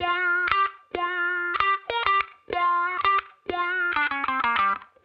Index of /musicradar/sampled-funk-soul-samples/95bpm/Guitar
SSF_StratGuitarProc2_95G.wav